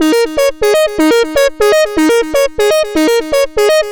FK Riff_122_Eb.wav